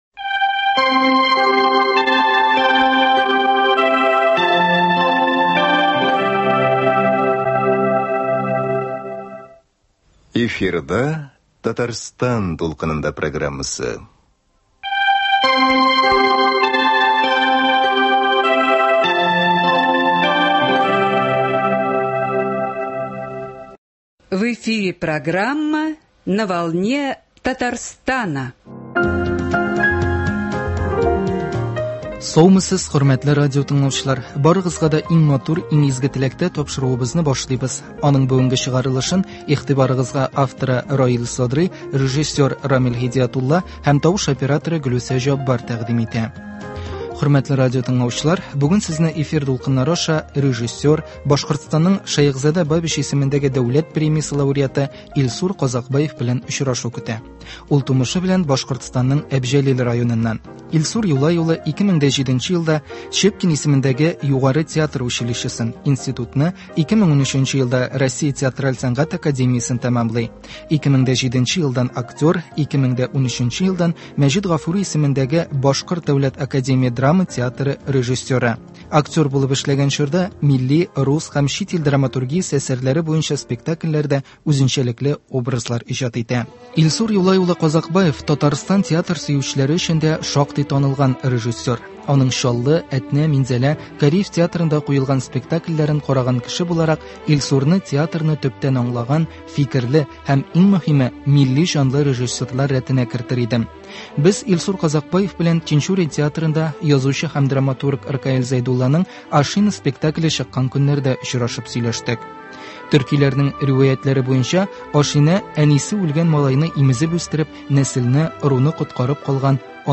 әнгәмә.